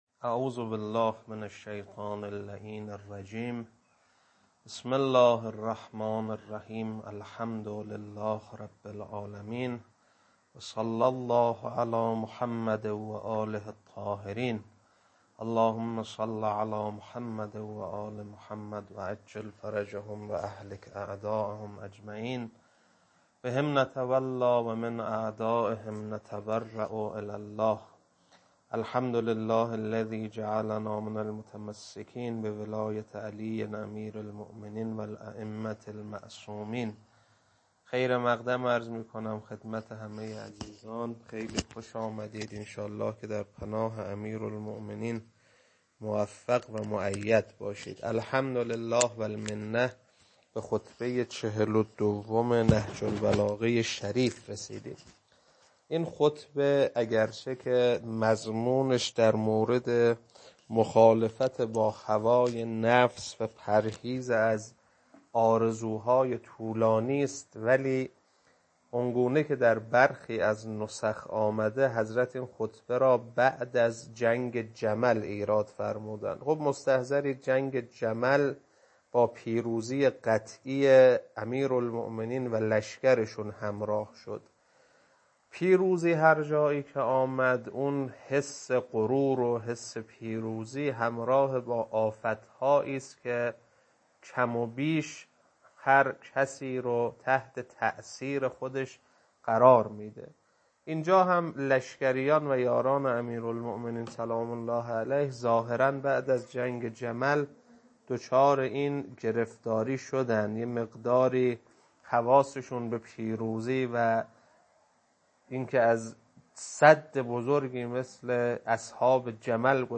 خطبه-42.mp3